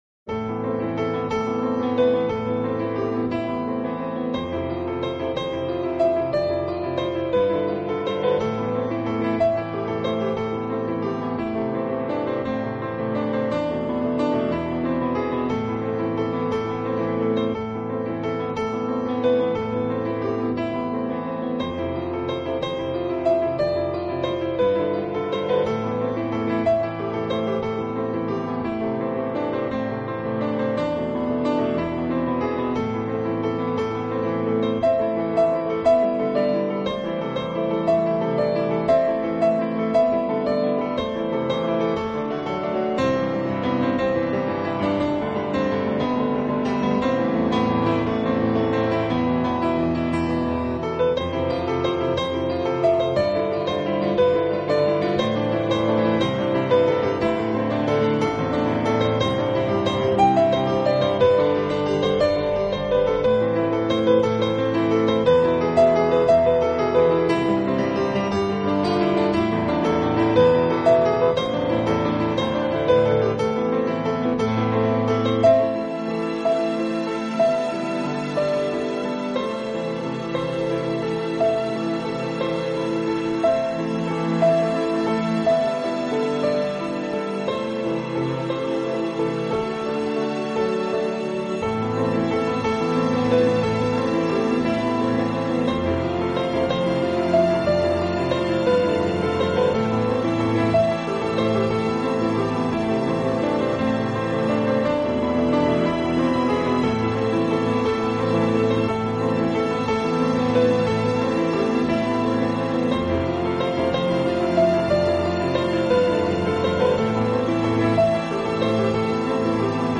但在表现上以现代流行手法为主，多以电子合成器演奏。